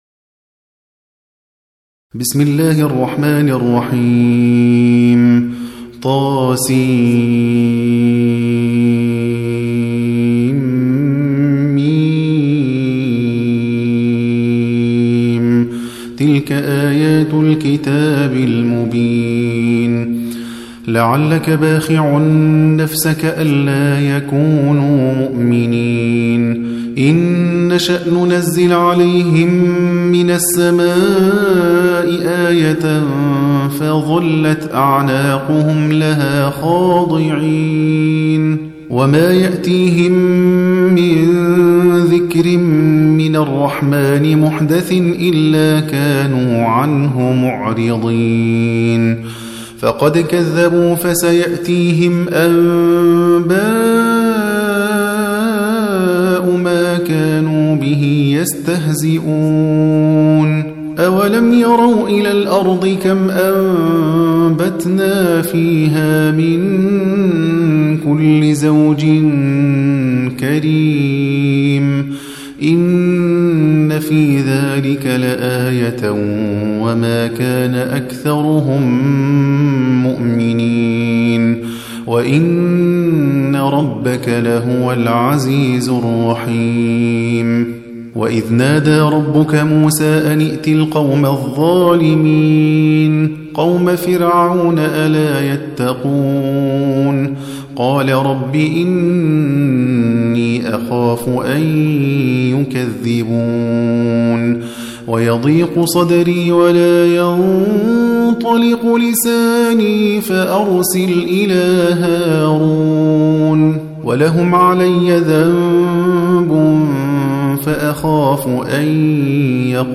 26. Surah Ash-Shu'ar�' سورة الشعراء Audio Quran Tarteel Recitation
Surah Sequence تتابع السورة Download Surah حمّل السورة Reciting Murattalah Audio for 26. Surah Ash-Shu'ar�' سورة الشعراء N.B *Surah Includes Al-Basmalah Reciters Sequents تتابع التلاوات Reciters Repeats تكرار التلاوات